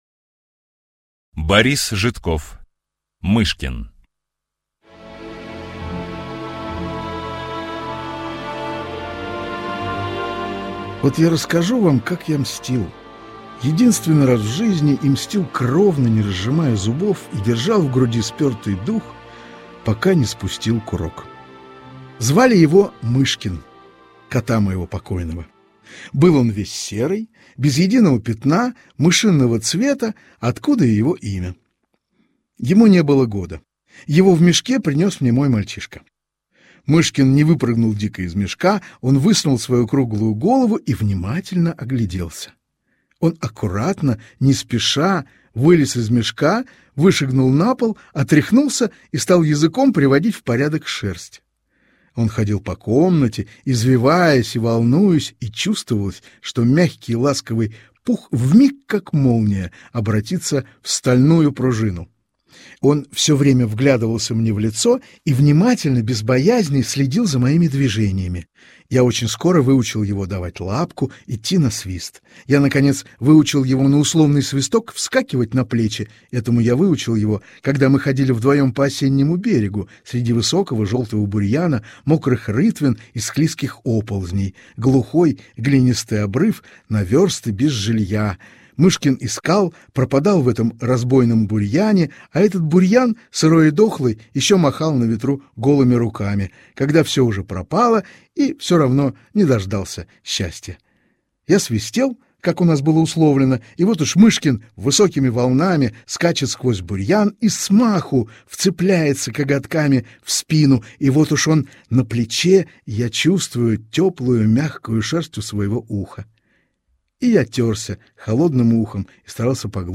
Мышкин - аудио рассказ Житкова - слушать онлайн